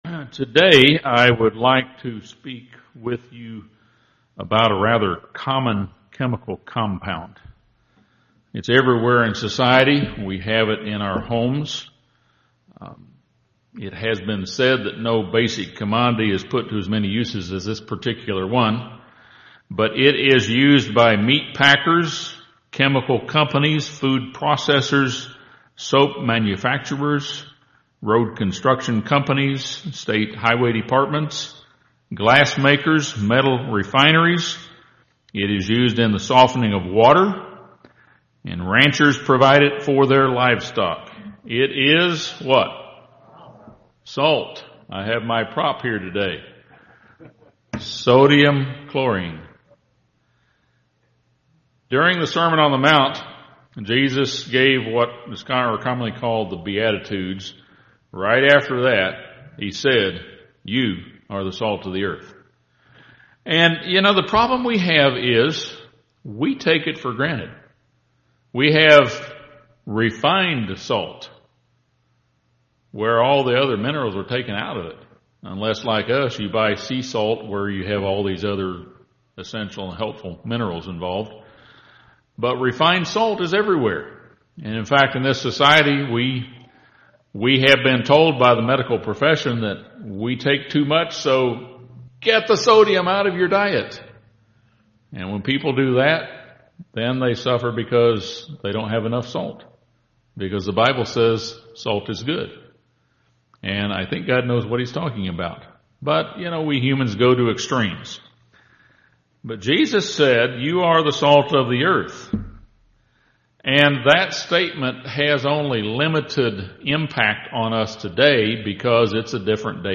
During the Sermon on the Mount, Jesus compared the disciples to salt. This sermon discusses various positive functions of salt: its importance in maintaining life, the preservation and seasoning of food, and in facilitating healing.